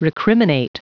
Prononciation du mot recriminate en anglais (fichier audio)
Prononciation du mot : recriminate